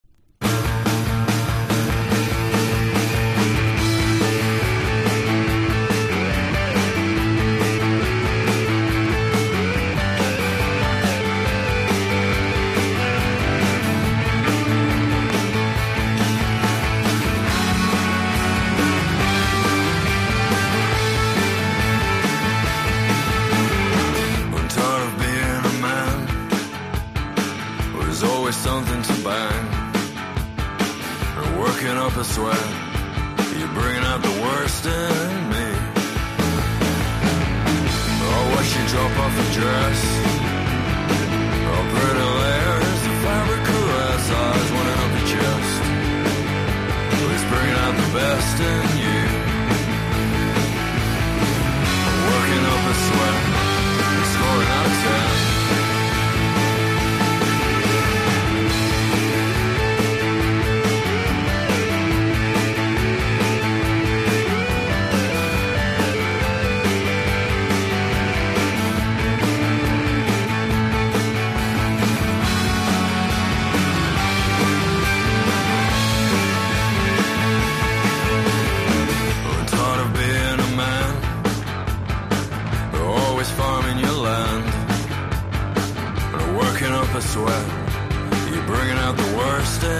1. 00S ROCK >
NEO ACOUSTIC / GUITAR POP
男女ツイン・ヴォーカル5ピース・バンド